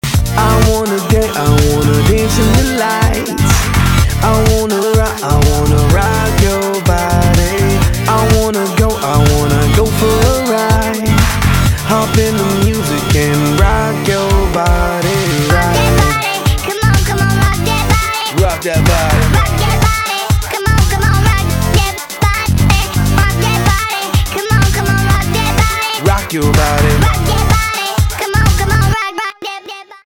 рэп
хип-хоп
басы
электроника